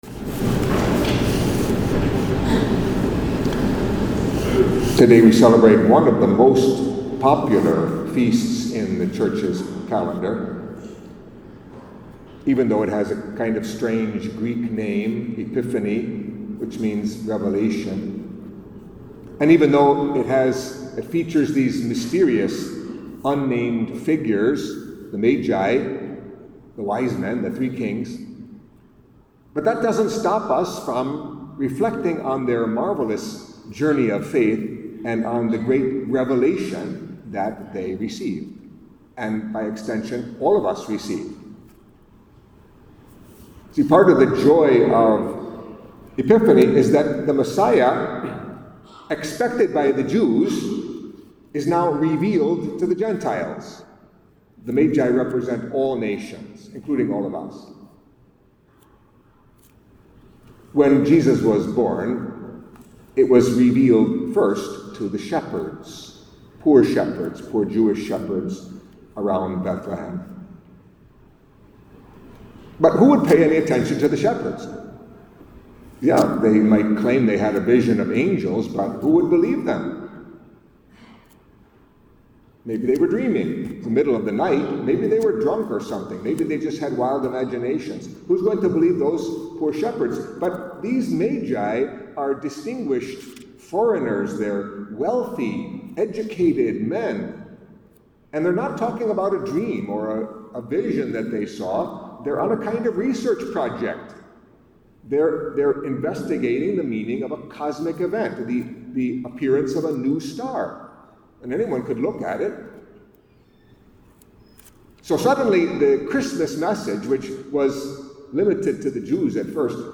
Catholic Mass homily for The Epiphany of the Lord